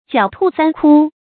注音：ㄐㄧㄠˇ ㄊㄨˋ ㄙㄢ ㄎㄨ
狡兔三窟的讀法